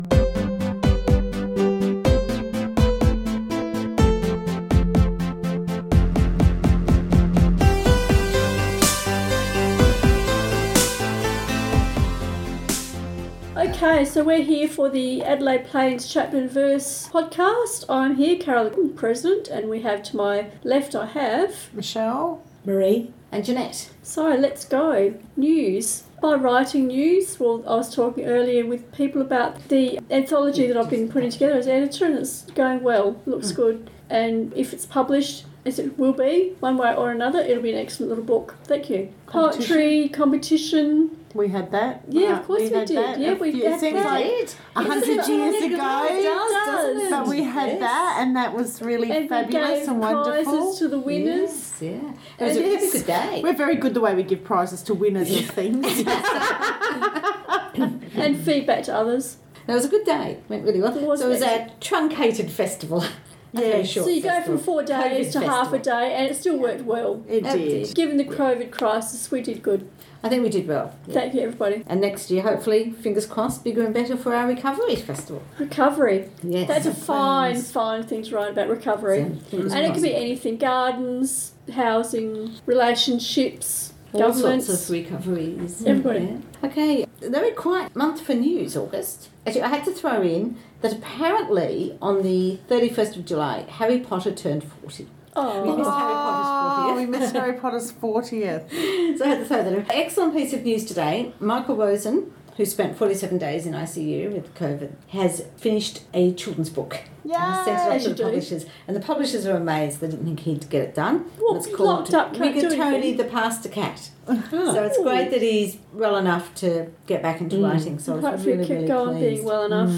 August Podcast Adelaide Plains Chapter and Verse monthly podcast recorded Wednesday 12th August, Gawler South.